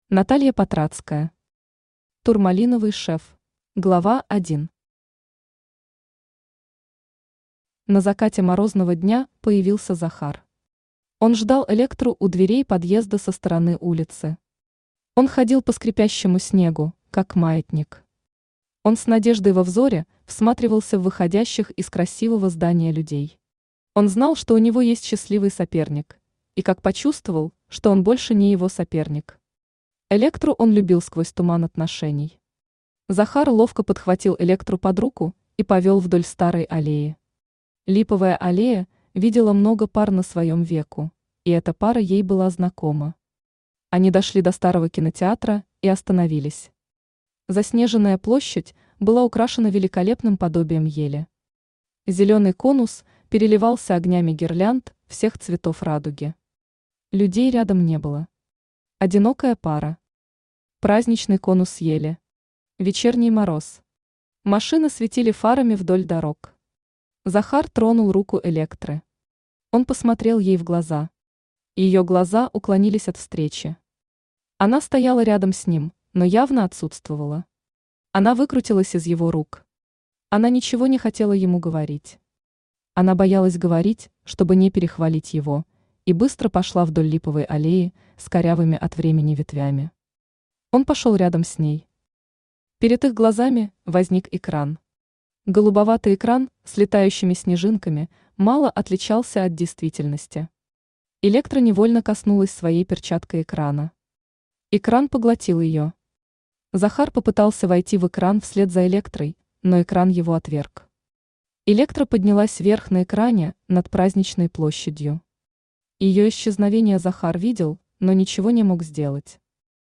Аудиокнига Турмалиновый шеф | Библиотека аудиокниг
Aудиокнига Турмалиновый шеф Автор Наталья Владимировна Патрацкая Читает аудиокнигу Авточтец ЛитРес.